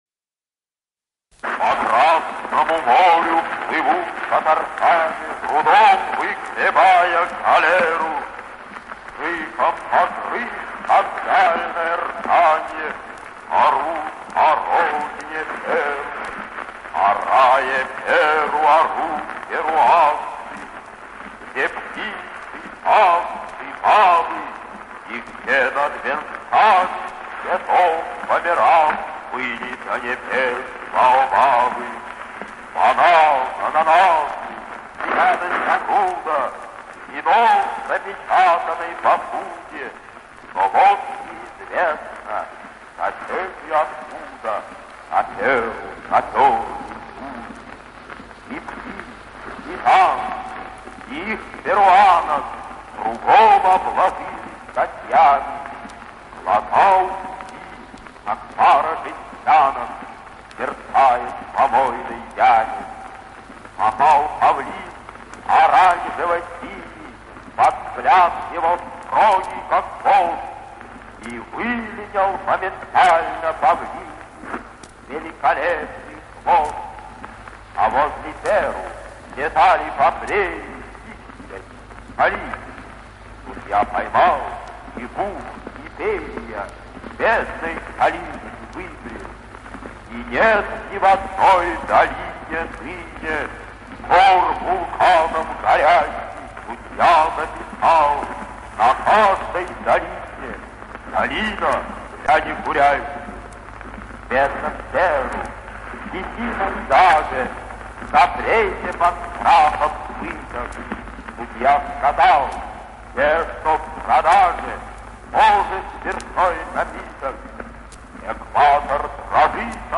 5. «Владимир Маяковский – Гимн судье… (автор)» /